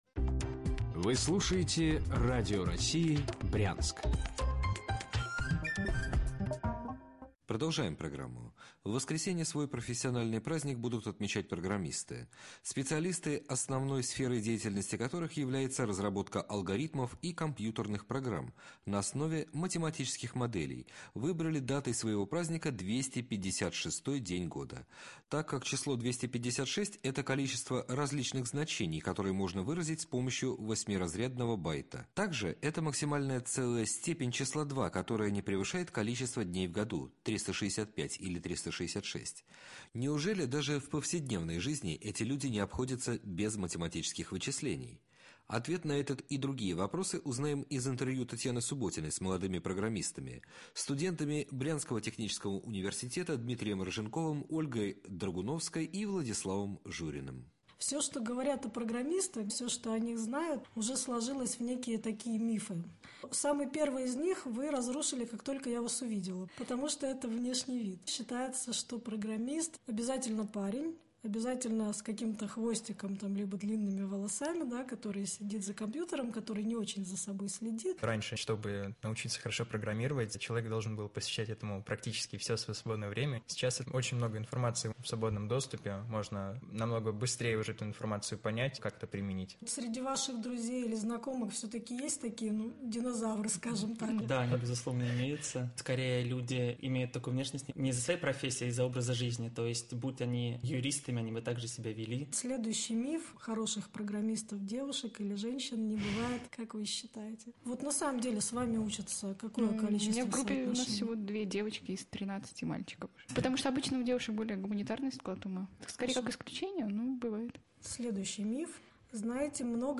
• Интервью преподавателей и студентов нашего вуза для Радио России 28/09/2015